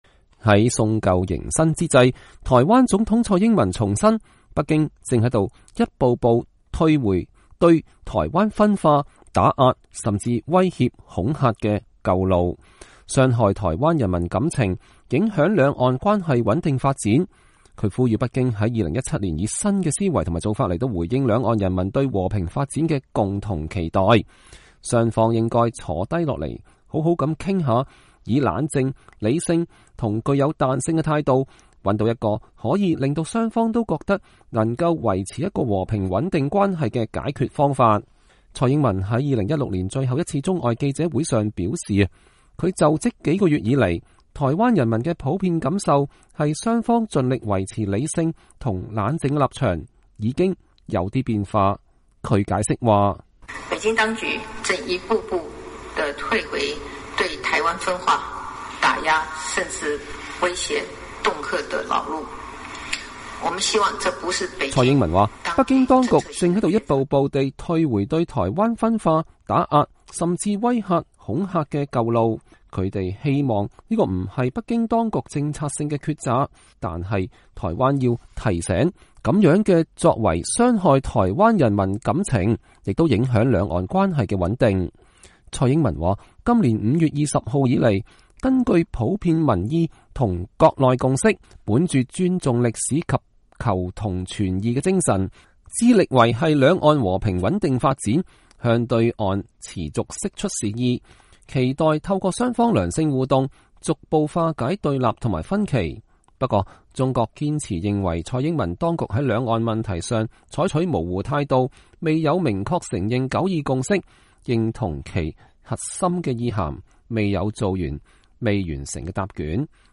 蔡英文在2016年最後一次中外記者會上表示，她就職幾個月來，台灣人民的普遍感受，是雙方盡力維持理性及冷靜的立場，已經有些變化。